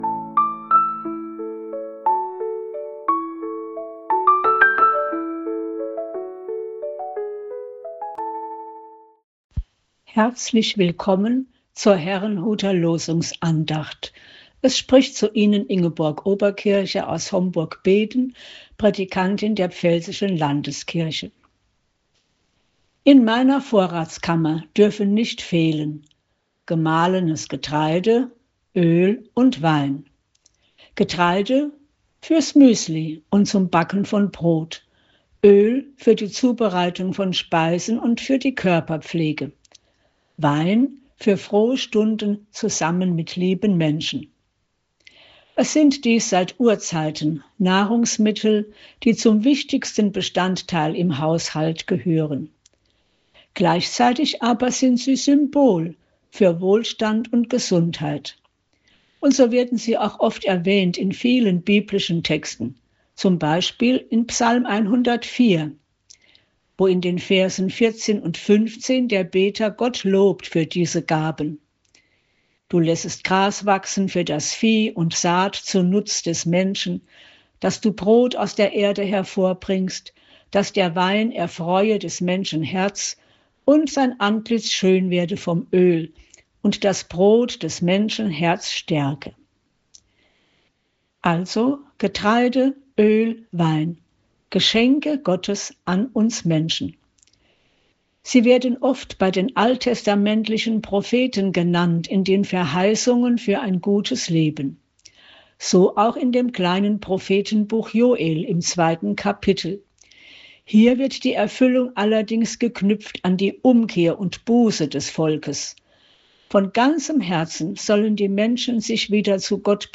Losungsandachten
Losungsandacht für Freitag, 30.01.2026